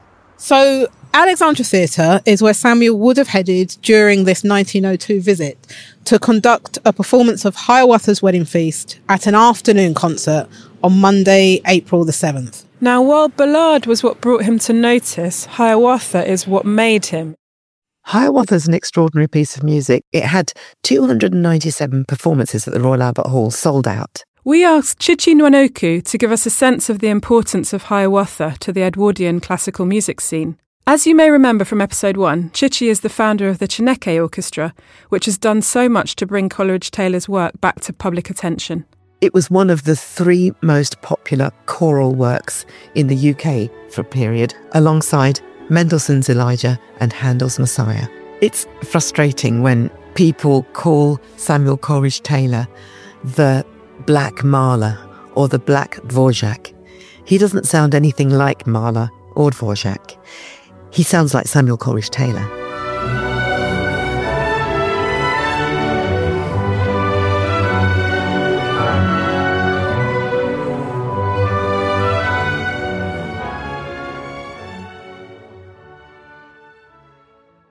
The audio project includes a three-part podcast series focusing on three specific journeys the composer took on the Great Western Railway during his rise to international fame between 1898 and 1902: to Gloucester, Newton Abbot and Swansea.
Blending immersive storytelling, expert interviews and archival research, the audio series invites listeners to follow in the footsteps of the composer as he navigated late Victorian and early Edwardian Britain as a jobbing conductor, musician and musical judge.